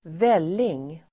Ladda ner uttalet
välling substantiv, gruel Uttal: [²v'el:ing] Böjningar: vällingen, vällingar Definition: ett slags soppa på mjöl m m Sammansättningar: havrevälling (gruel made from oats), frukostvälling (breakfast gruel)